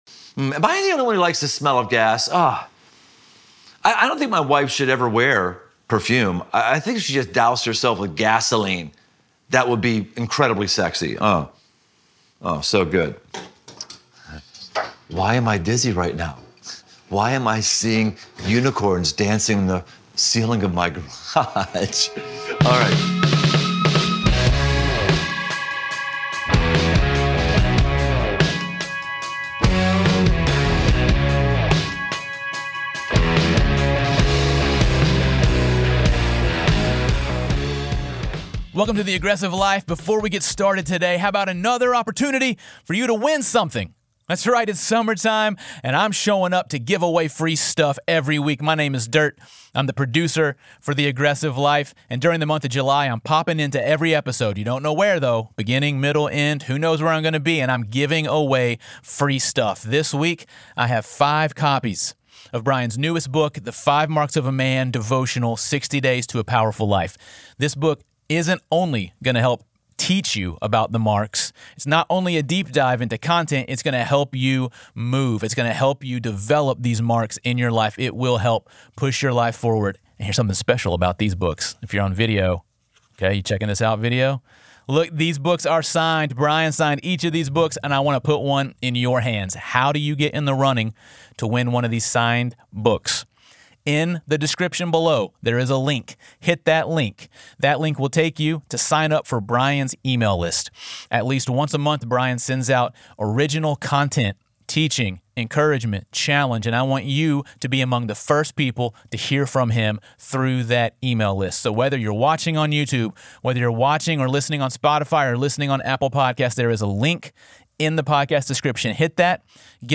Recorded live in his garage